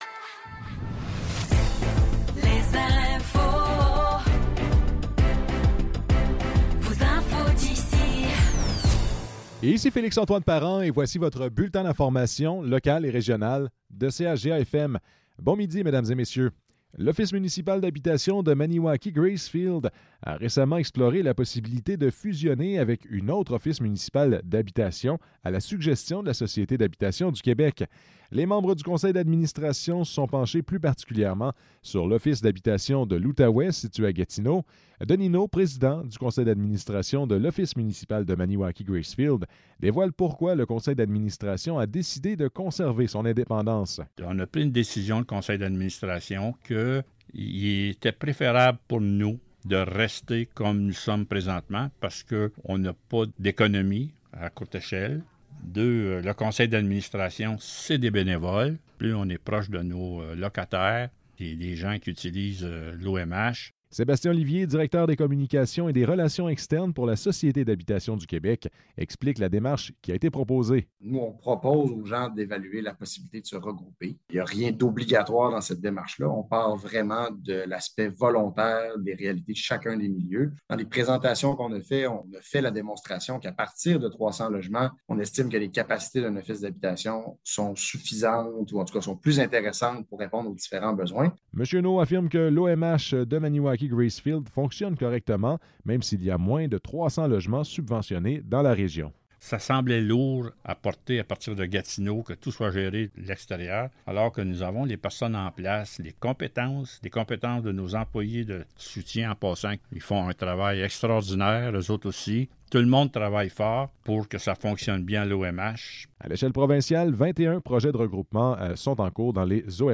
Nouvelles locales - 20 décembre 2023 - 12 h